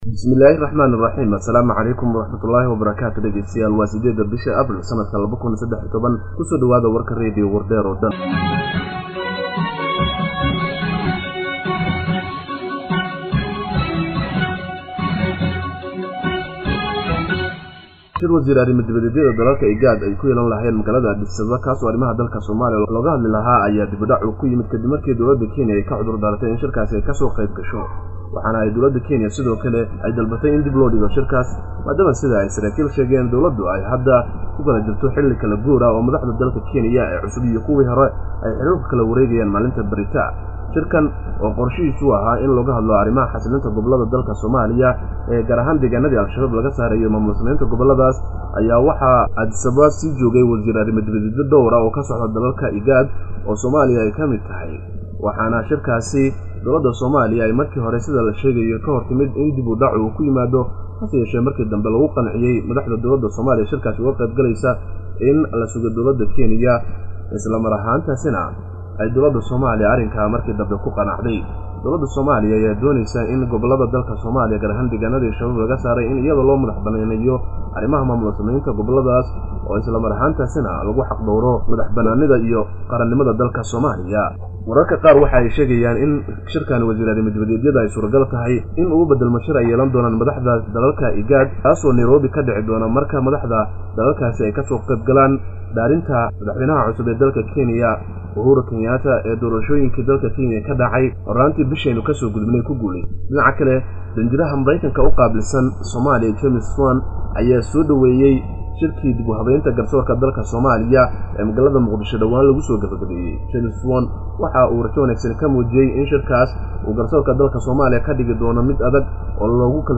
Dhageyso Warka Radio Wardheer - WardheerNews